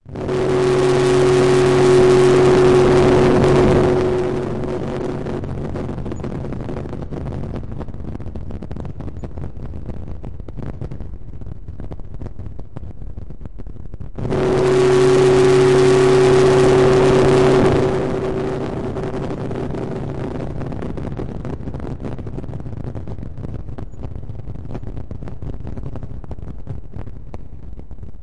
描述：recorded from elektrostudio model mini free vsti , random parameters algorithm in flstudio 3.0
标签： synth generator oscillator